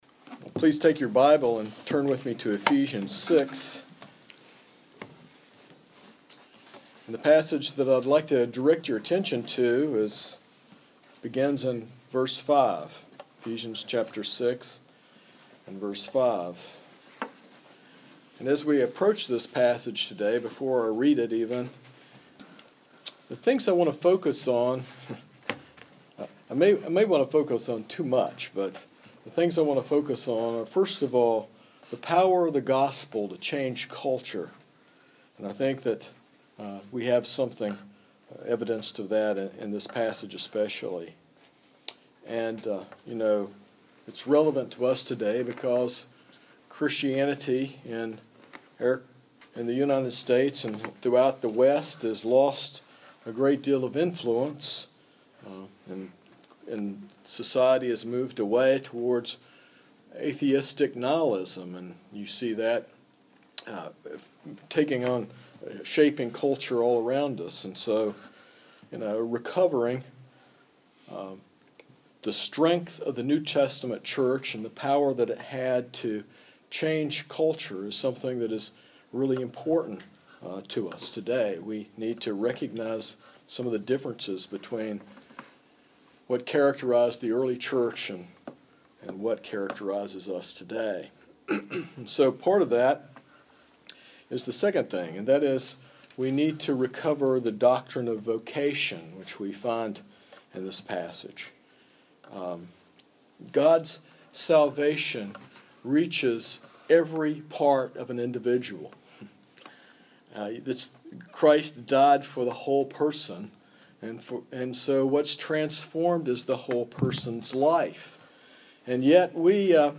This sermon from Ephesians 6 challenges listeners to embrace the transformative power of the gospel, particularly through the doctrine of vocation. It argues that all work, even seemingly menial tasks, is a calling from God and a means of blessing, urging Christians to live out their faith in every aspect of life.